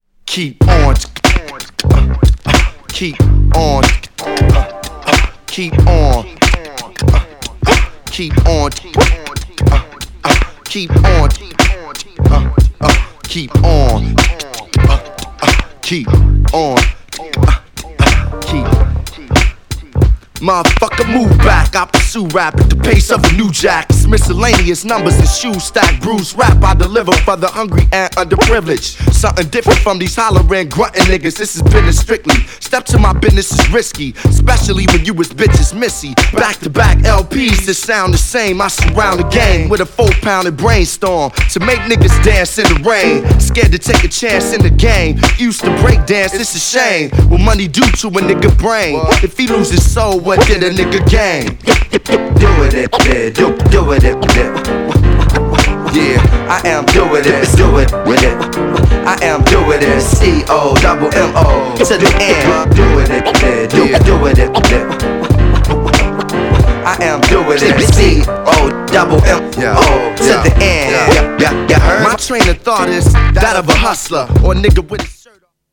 GENRE Hip Hop
BPM 91〜95BPM